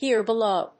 アクセントhére belów